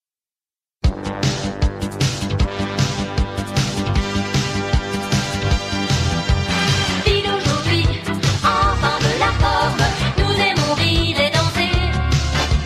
Générique de quel DA ?